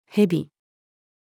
蛇-snake-female.mp3